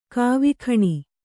♪ kāvikhaṇi